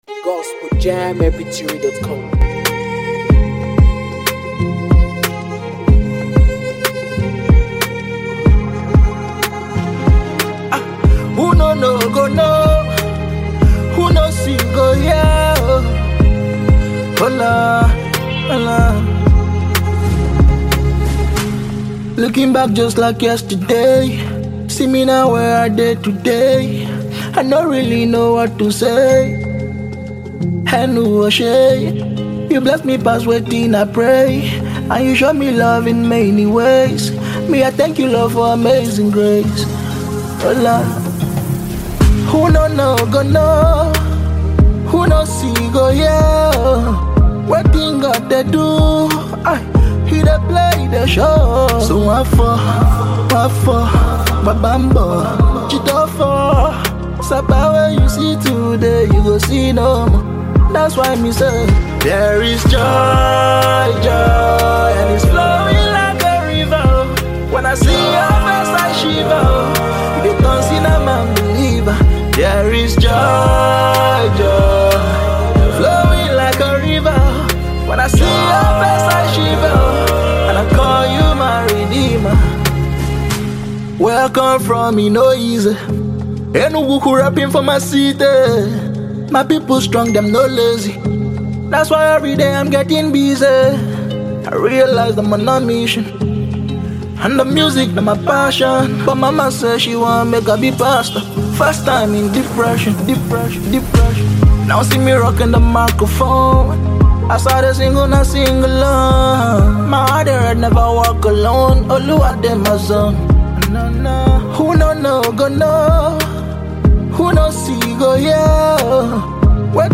Afro beatmusic
an uplifting song
a powerful gospel-inspired song
a vibrant and spirit-filled song that speaks life
Blending meaningful lyrics with an uplifting melody